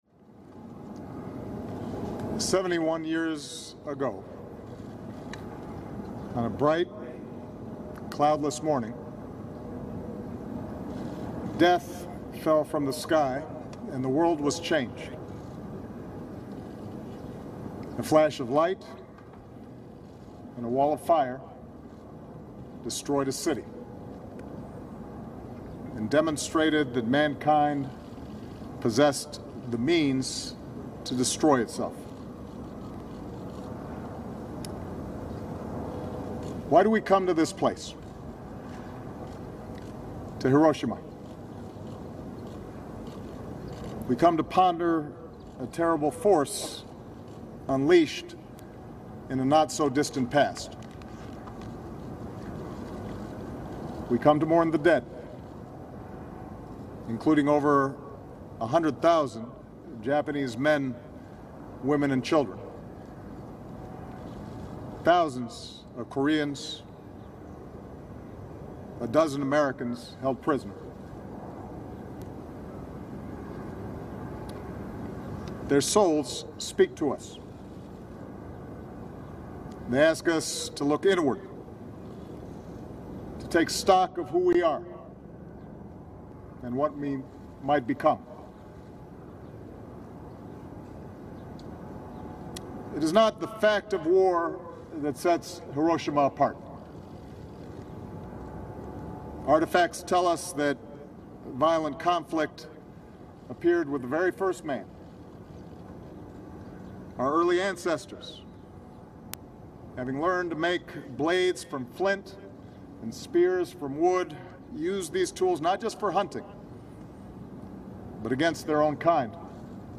United States President Barack Obama and Japanese Prime Minister Shinzo Abe deliver remarks following a wreath-laying ceremony at the Hiroshima Peace Memorial in Japan. Obama describes the horrors of World War Two and the destruction of the first atomic bomb and says that the U.S. and Japan have made choices since the Hiroshima bombing that should give the world hope and that the world needs to change its mindset about war and work toward peaceful cooperation. Prime Minister Abe, via a translator, talks about his address to the U.S. Congress in 2015 on the 70th anniversary of the end of World War Two and about the friendship between the U.S. and Japan.